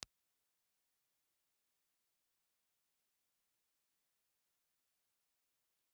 Ribbon
Impulse Response file of the RCA Junior ribbon microphone.
RCA_MI4010_IR.wav